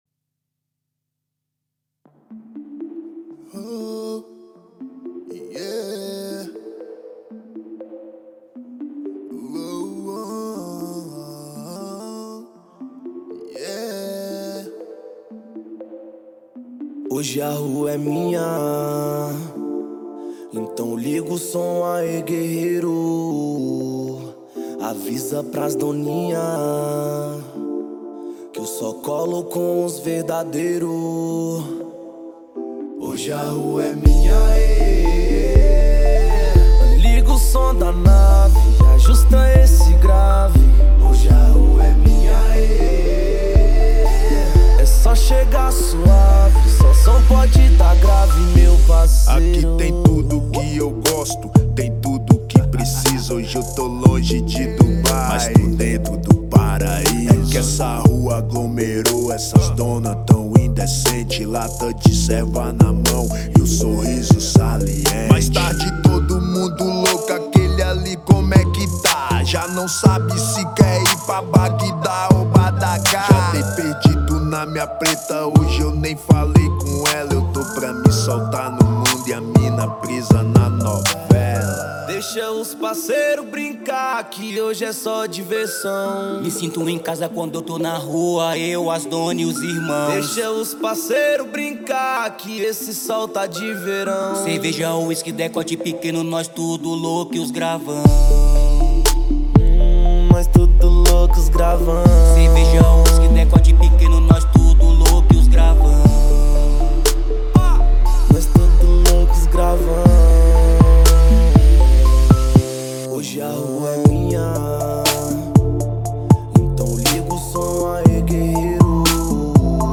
2025-02-23 16:15:01 Gênero: Rap Views